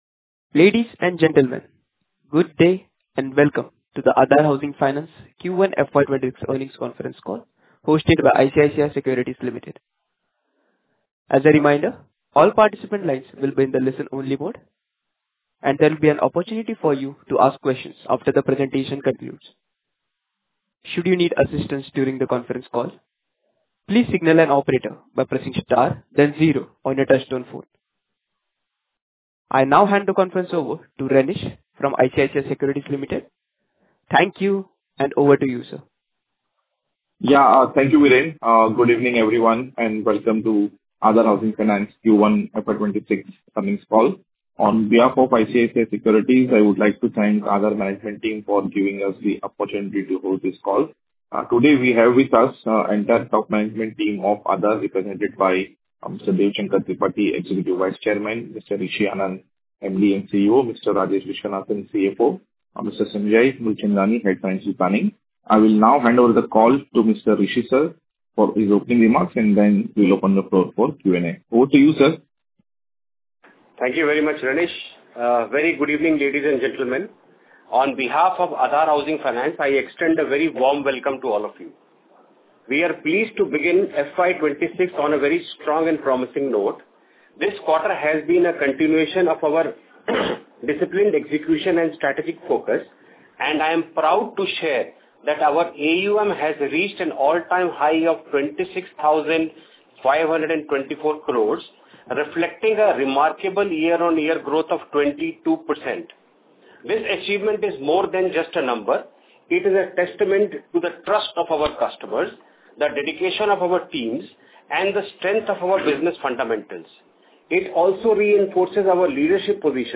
Earnings Call Invite -- Investor Presentation -- Audio Recordings of Earning Conference Call for the Quarter - FY 25-26